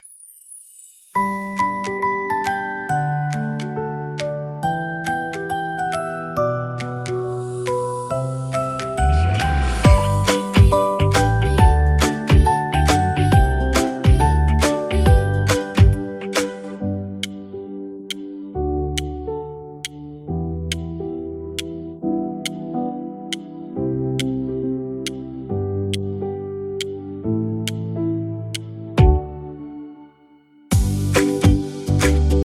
wzruszająca piosenka dla dzieci
• łatwa melodia odpowiednia dla młodszych dzieci,